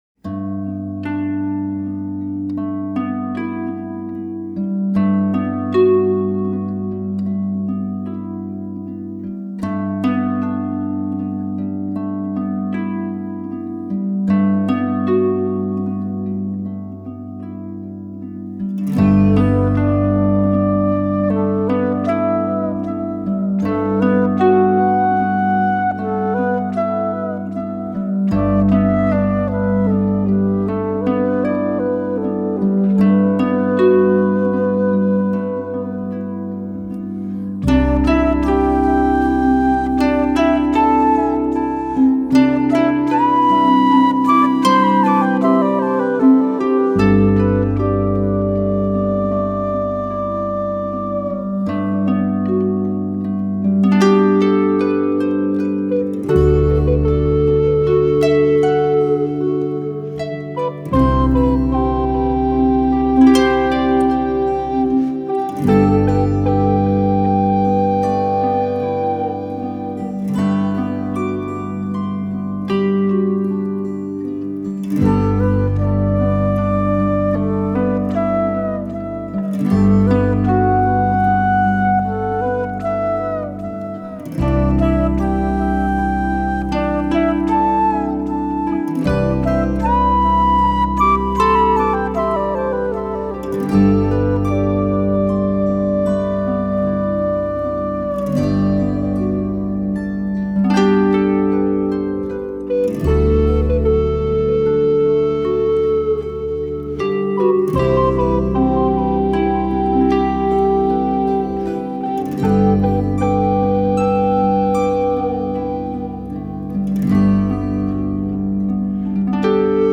gentle and soulful music